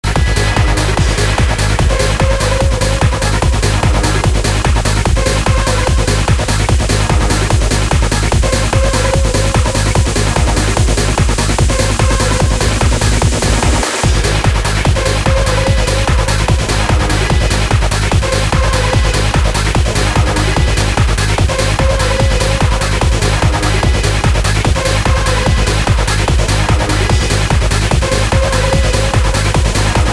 /160kbps) Описание: Любителям Hard!